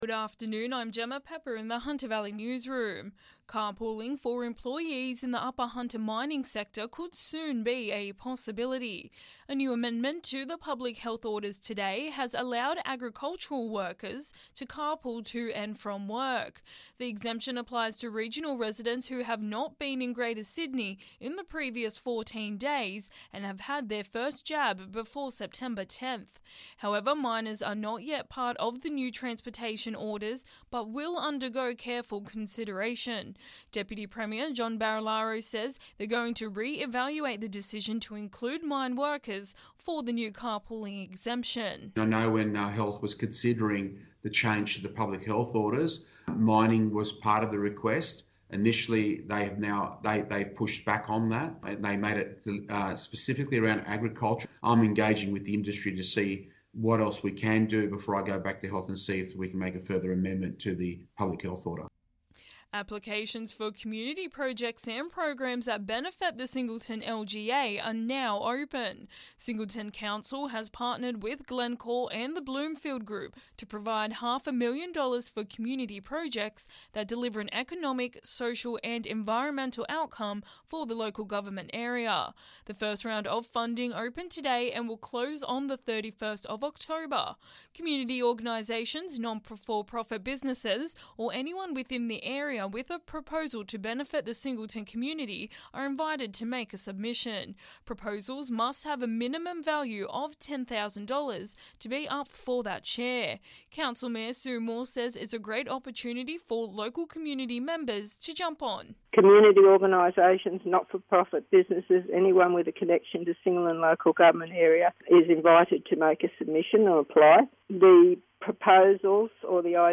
Listen: Hunter Local News Headlines 01/09/2021